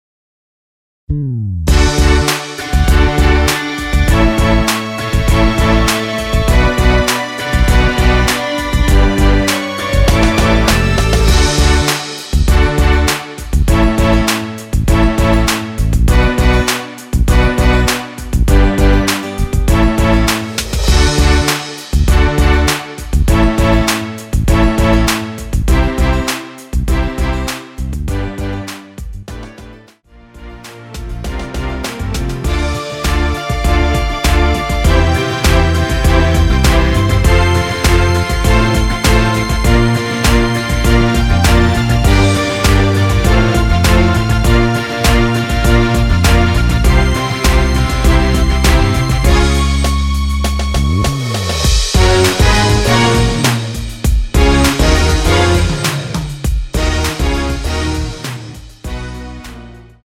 원키에서(+3)올린 MR입니다.
앞부분30초, 뒷부분30초씩 편집해서 올려 드리고 있습니다.
중간에 음이 끈어지고 다시 나오는 이유는